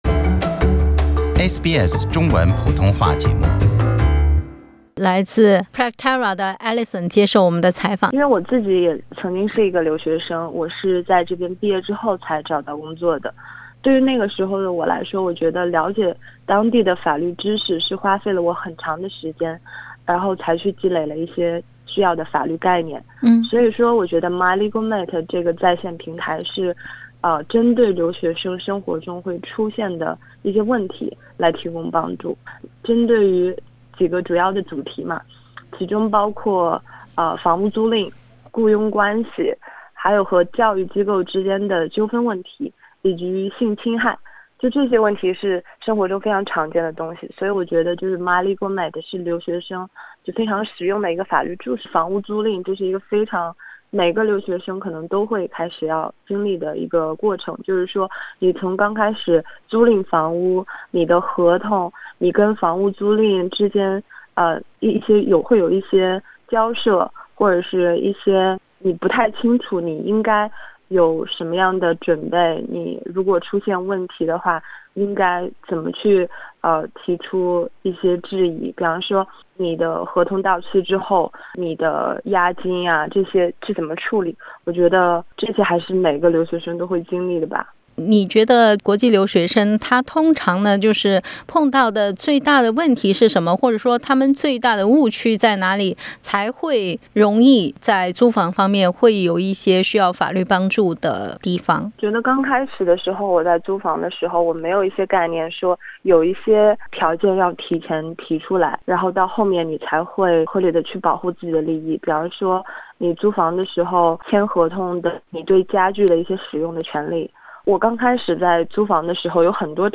09:04 Source: Sydney city council SBS 普通话电台 View Podcast Series Follow and Subscribe Apple Podcasts YouTube Spotify Download (16.63MB) Download the SBS Audio app Available on iOS and Android 留学法律咨询哪四个话题最热门？ 悉尼市推出了一款手机APP，为留学生提供多语种免费法律咨询，主要针对就业、住房、与校方纠纷、性侵犯这四大必要法律专项提供服务。